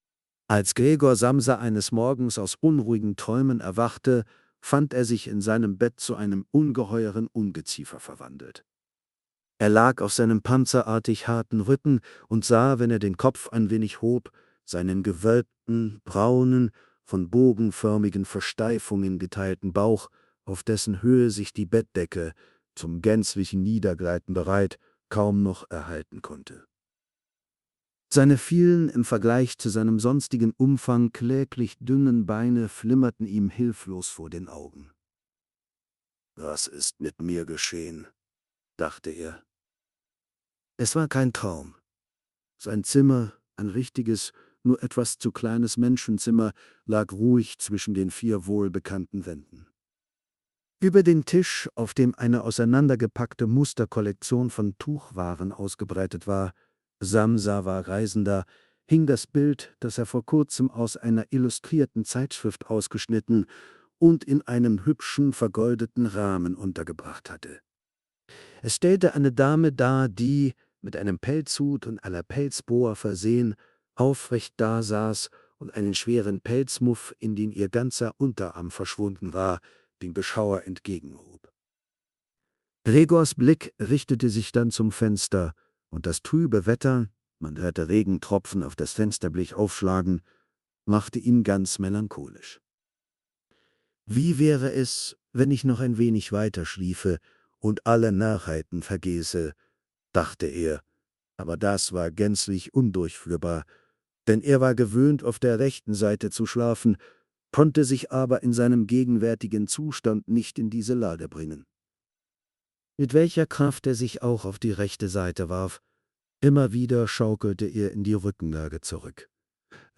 Long-form narration samples
Experience extended passages in English and German from Tontaube, the world's most natural-sounding model for audiobooks.
Hörbuch-Erzählung — Deutsch
tontaubeV0-audiobook-de.m4a